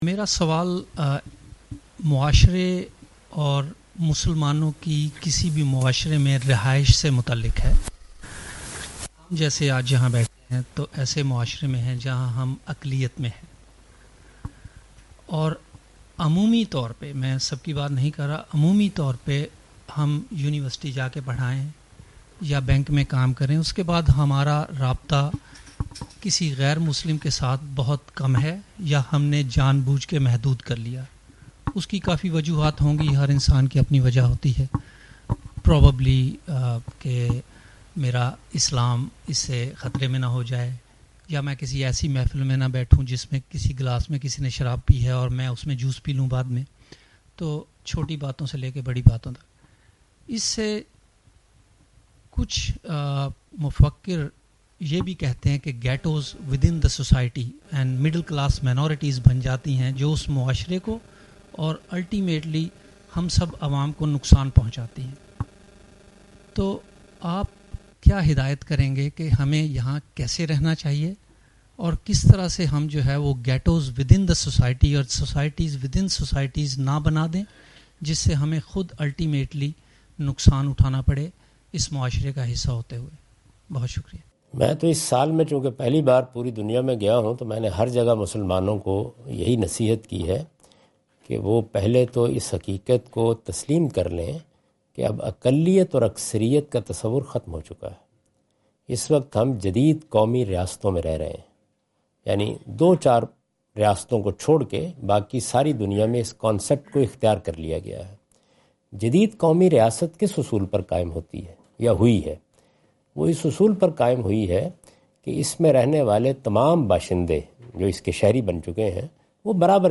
Javed Ahmad Ghamidi answer the question about "Establishing Separate Communities in a Non-Muslim Society" during his Australia visit on 11th October 2015.
جاوید احمد غامدی اپنے دورہ آسٹریلیا کے دوران ایڈیلیڈ میں "غیر مسلم معاشرے میں الگ شناخت اپنانا" سے متعلق ایک سوال کا جواب دے رہے ہیں۔